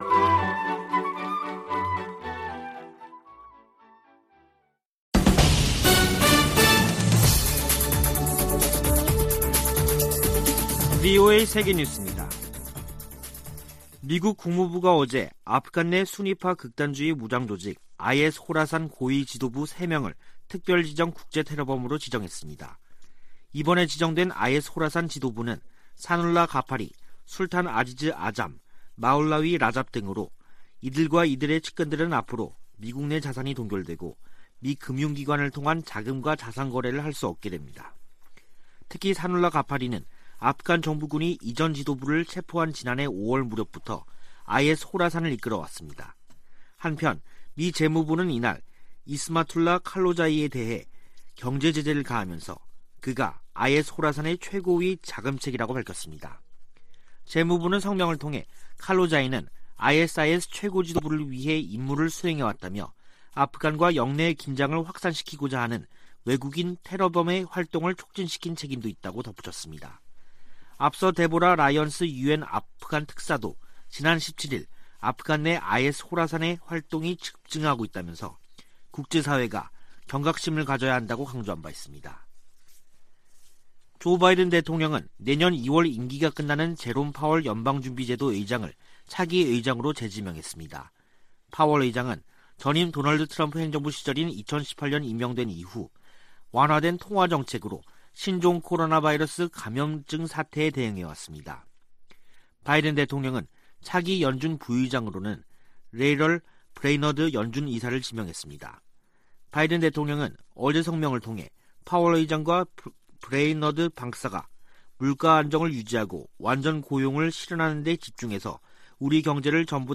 VOA 한국어 간판 뉴스 프로그램 '뉴스 투데이', 2021년 11월 23일 3부 방송입니다. 세계 각국이 북한 해외 노동자 송환 보고서를 유엔 안보리에 보고하도록 돼 있지만 보고 비율은 20% 미만에 그치고 있습니다. 미국이 핵 문제와 관련해 이란과 ‘간접 협상’을 재개하지만 북한과의 협상은 여전히 재개 조짐이 없습니다. 미 국무부는 북한 등 문제 해결에 미,한, 일 3각 공조의 중요성을 거듭 강조하며, 지난주 열린 3국 차관협의회는 매우 건설적이었다고 밝혔습니다.